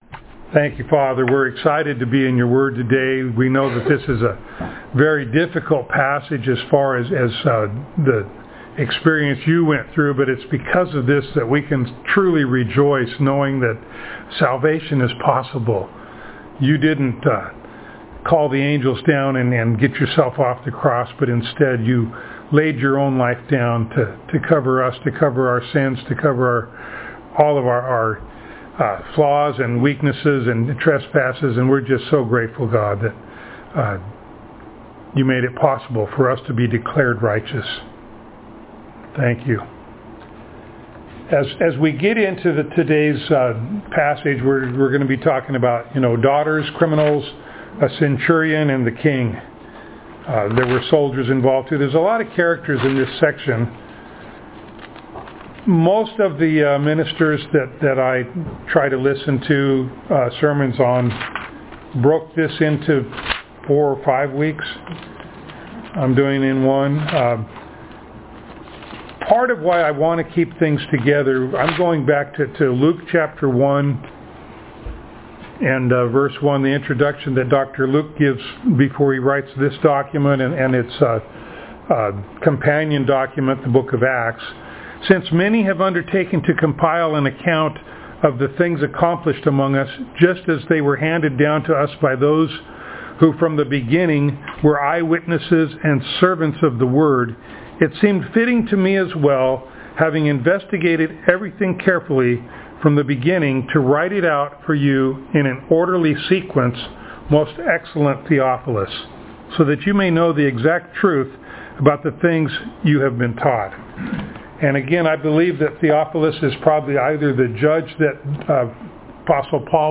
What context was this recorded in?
Passage: Luke 23:26-49 Service Type: Sunday Morning